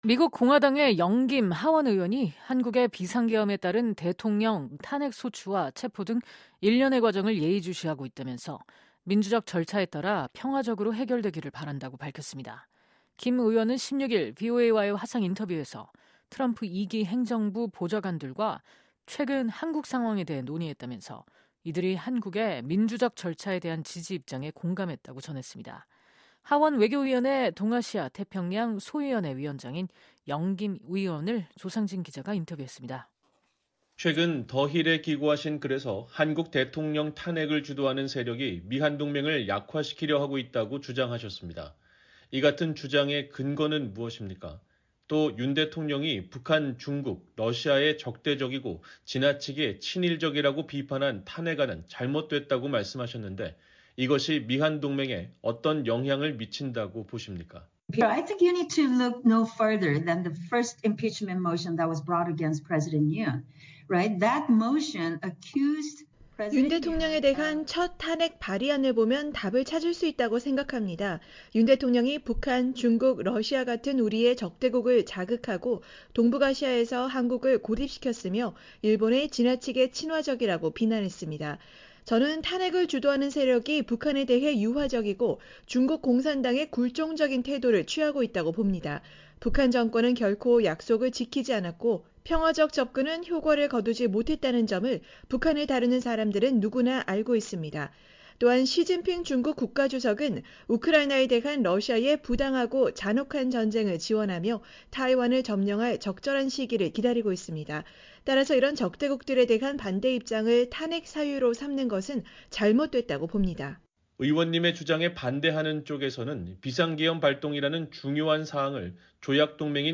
미국 공화당의 영 김 하원의원이 한국의 비상계엄에 따른 대통령 탄핵 소추와 체포 등 일련의 과정을 예의주시하고 있다면서 민주적 절차에 따라 평화적으로 해결되기를 바란다고 밝혔습니다. 김 의원은 16일 VOA와의 화상 인터뷰에서 트럼프 2기 행정부 보좌관들과 최근 한국 상황에 대해 논의했다면서, 이들이 한국의 민주절 절차에 대한 지지 입장에 공감했다고 전했습니다.